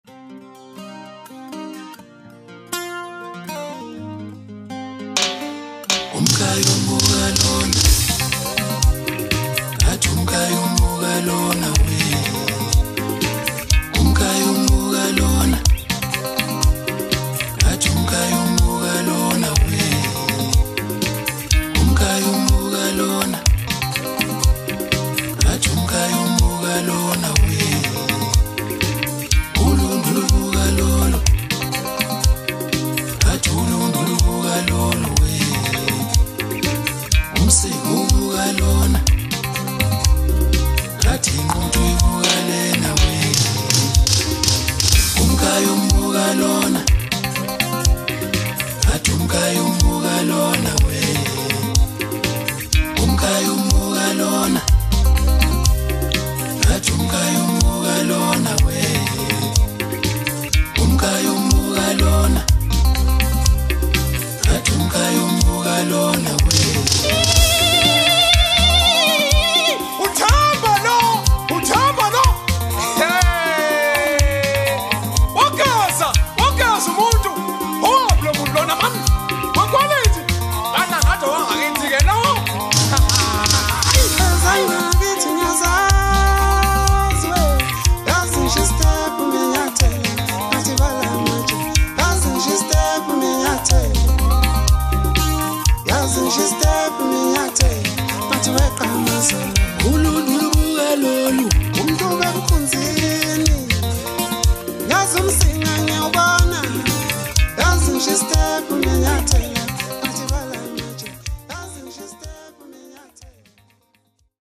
smooth, poignant, and incredibly fascinating